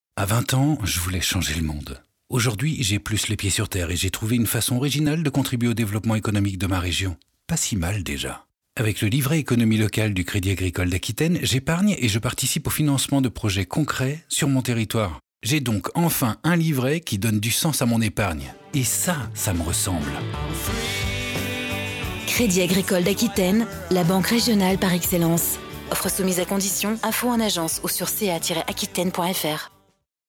My voice is a cameleon voice.
Sprechprobe: eLearning (Muttersprache):